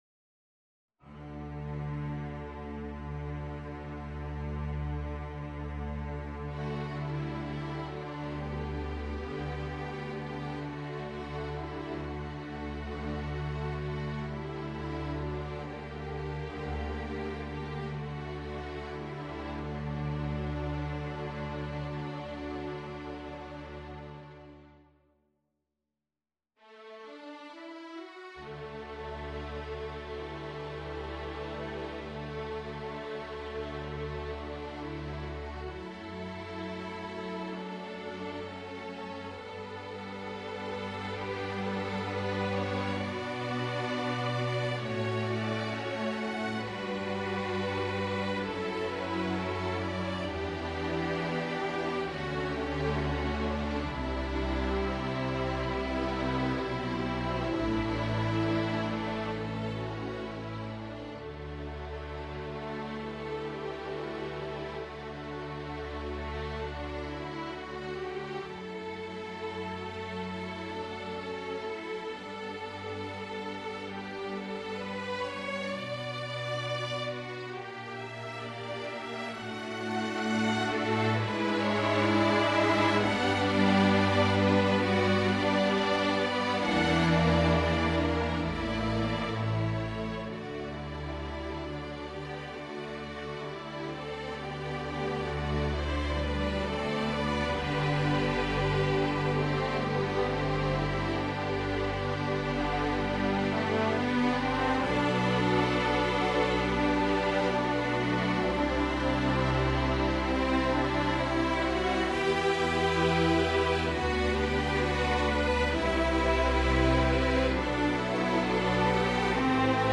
Per formazioni varie